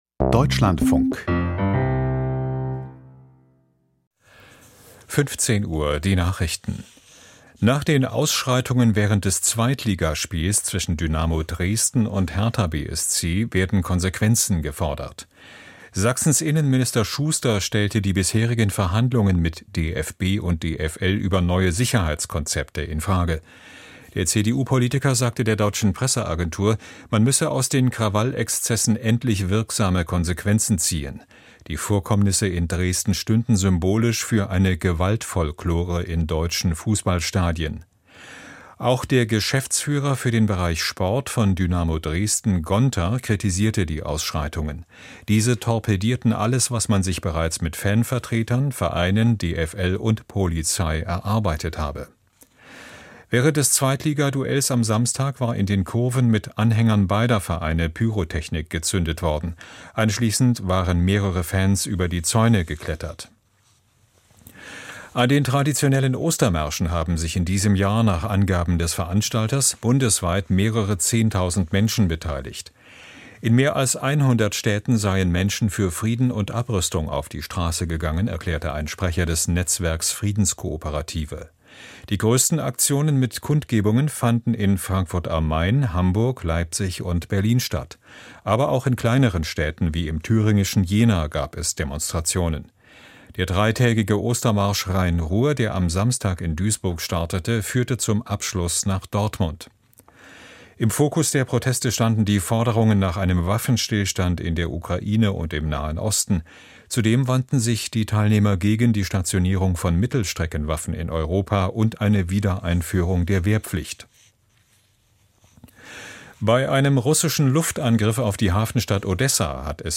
Die Nachrichten vom 06.04.2026, 15:00 Uhr